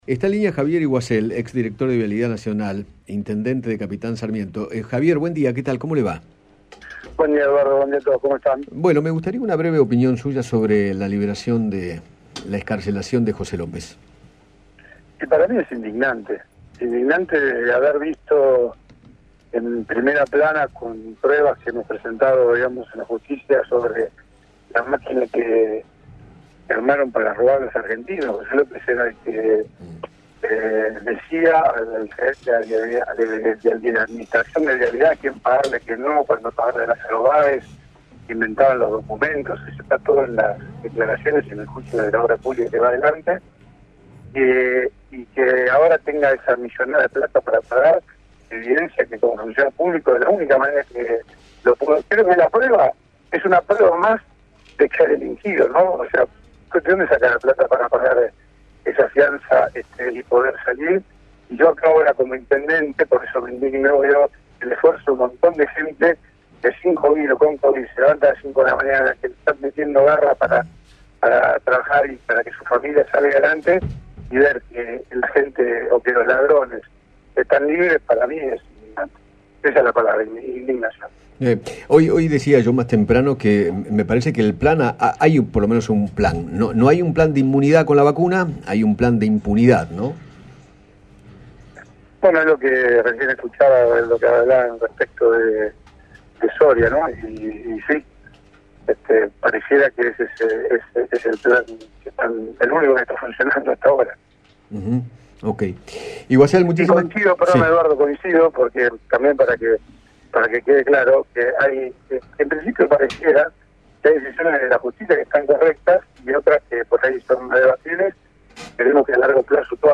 Javier Iguacel, intendente de Capitán Sarmiento, dialogó con Eduardo Feinmann sobre la liberación de José López en la causa por los bolsos con 9 millones de dólares.